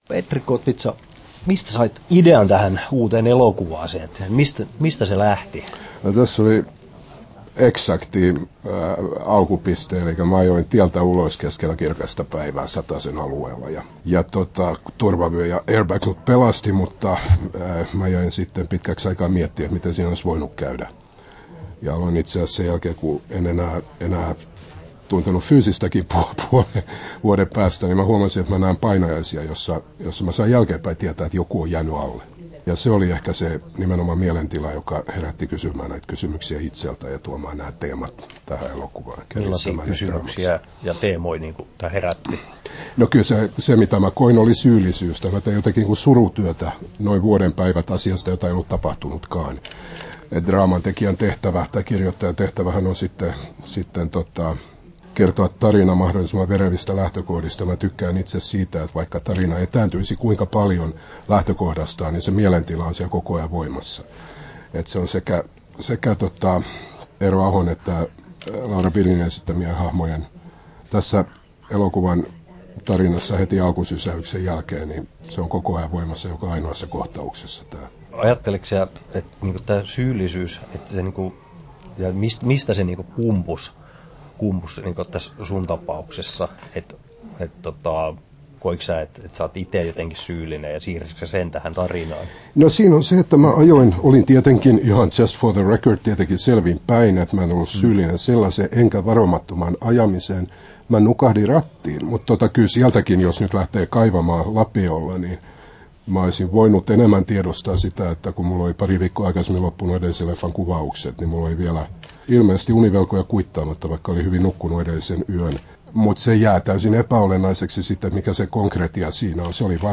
Haastattelut
9'28" Tallennettu: 31.3.2015, Turku Toimittaja